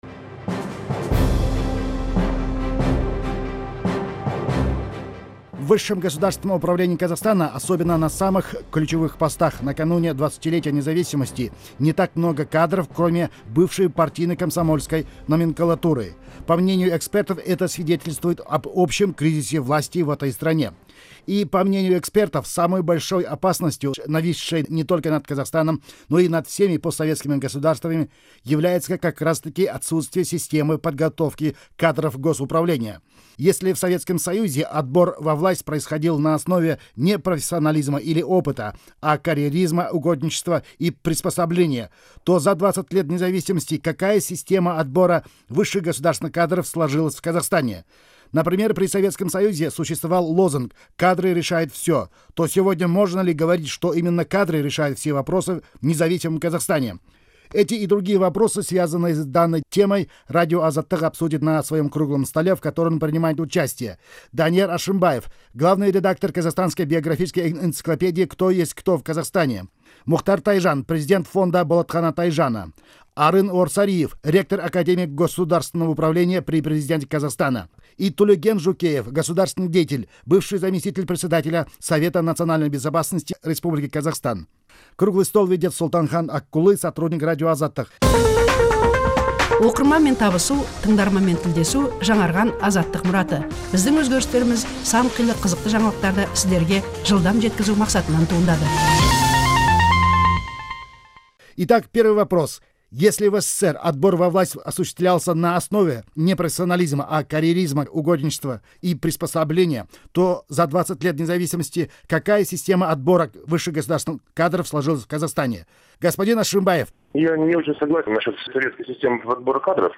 Запись круглого стола